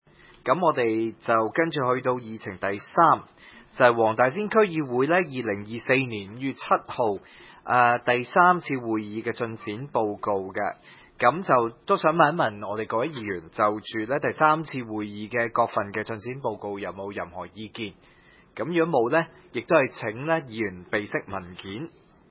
區議會大會的錄音記錄
黃大仙區議會會議室